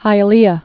(hīə-lēə)